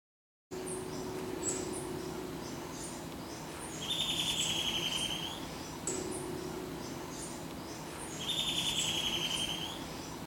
Hormiguero Vientriblanco (Myrmeciza longipes)
Nombre en inglés: White-bellied Antbird
País: Colombia
Provincia / Departamento: Tolima
Condición: Silvestre
Certeza: Vocalización Grabada